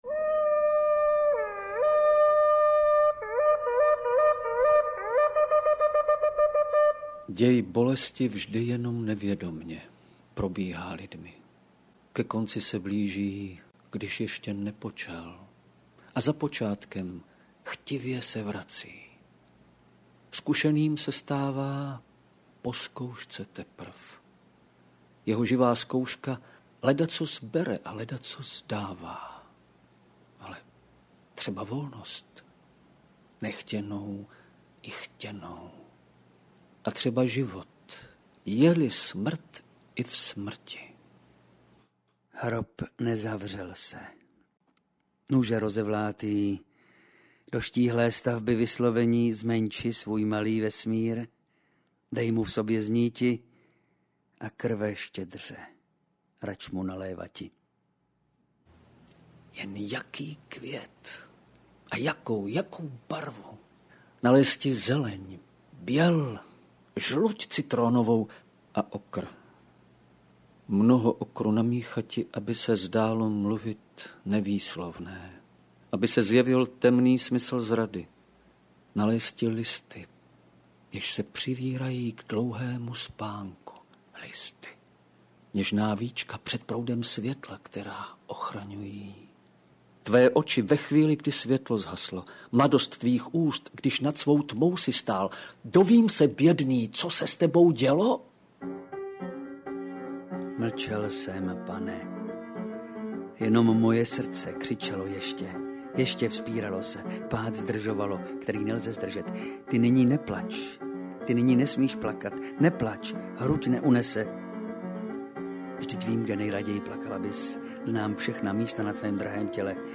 Interpreti:  Vladimír Javorský, Jan Potměšil
AudioKniha ke stažení, 9 x mp3, délka 54 min., velikost 49,0 MB, česky